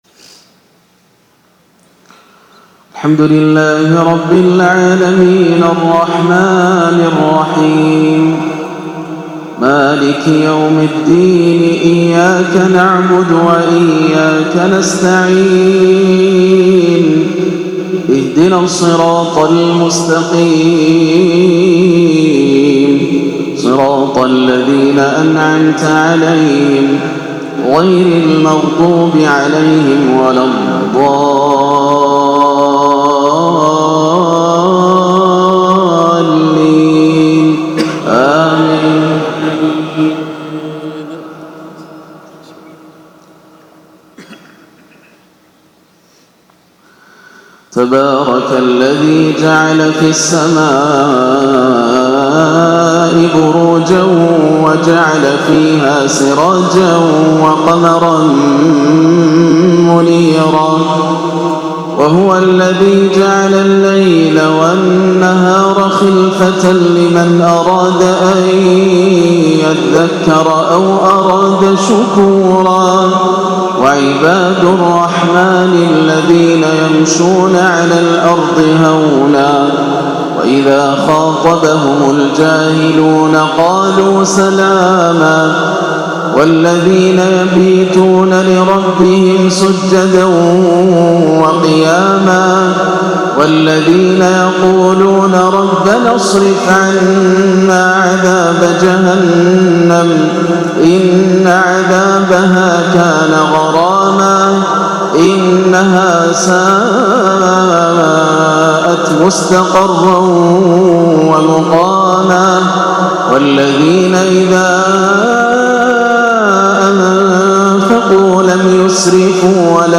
عشاء الأربعاء 2-4-1439هـ خواتيم سورتي الفرقان61-77 و الواقعة 75-96 > عام 1439 > الفروض - تلاوات ياسر الدوسري